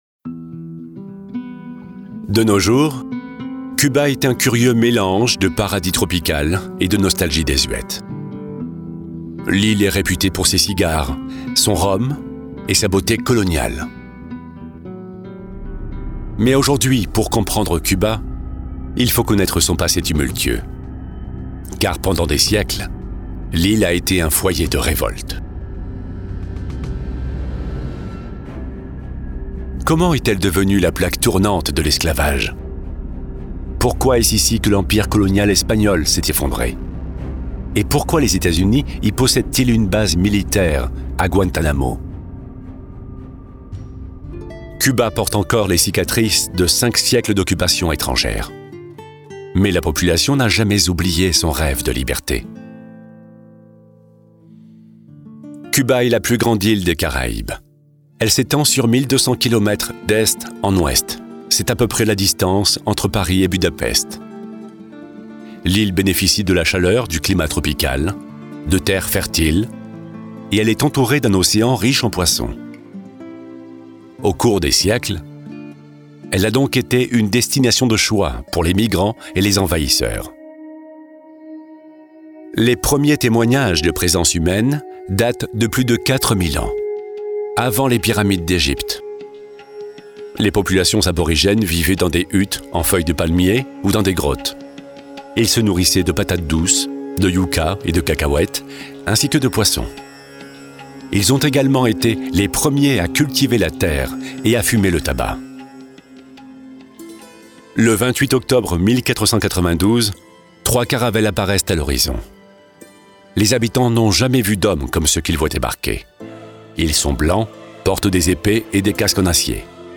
NARRATOR (cover)
Middle Aged
His deep and reassuring voice makes him the official voice of several radio stations.
He records his voice daily on a NEUMANN TLM 49 microphone, a large diaphragm microphone with a renowned warm sound.